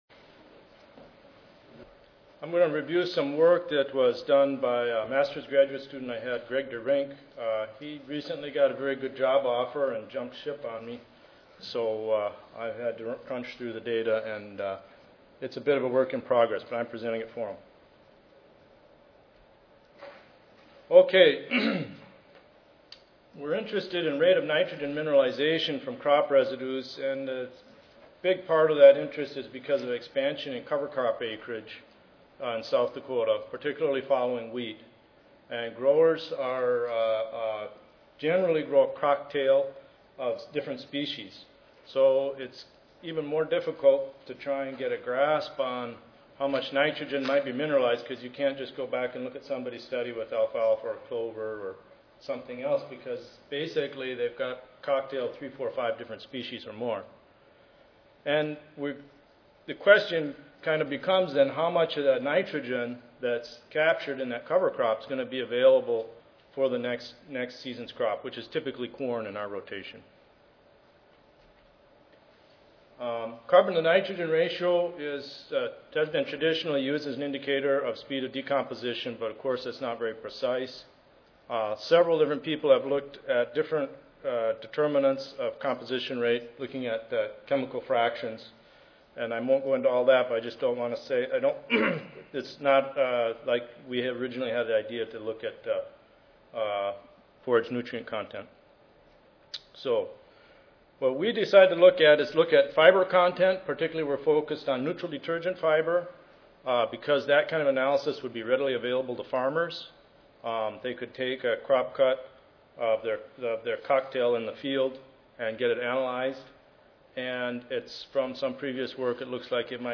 S04 Soil Fertility & Plant Nutrition Session: Nitrogen and Crop Production: I (ASA, CSSA and SSSA Annual Meetings (San Antonio, TX - Oct. 16-19, 2011))
USDA-ARS Recorded Presentation Audio File